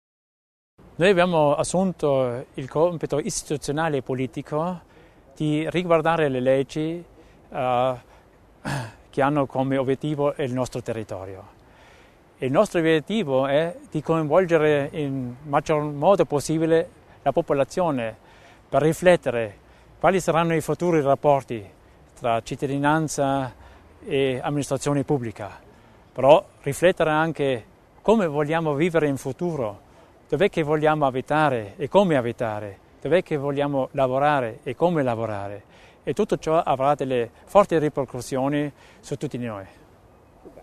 L'Assessore Theiner spiega l'importanza degli incontri dedicati al territorio